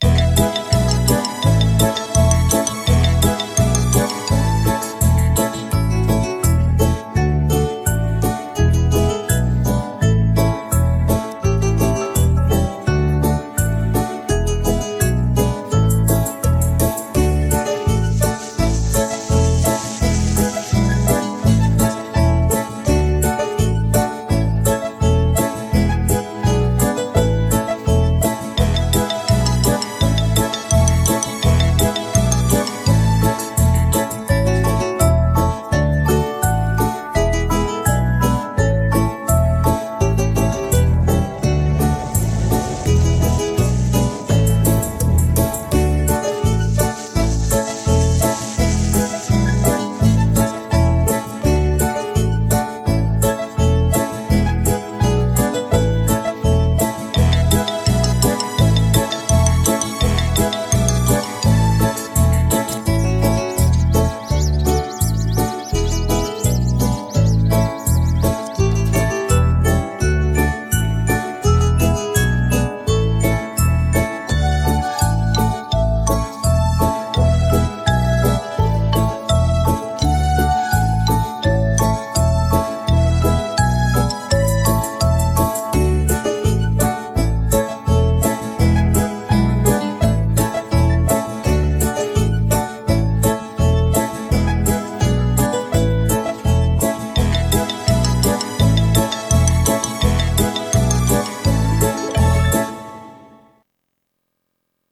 Главная / Песни для детей / Песни про осень
Слушать или скачать минус